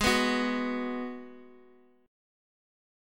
G#m7 chord
Gsharp-Minor 7th-Gsharp-x,x,x,3,2,1-1-down-Guitar-Open F.m4a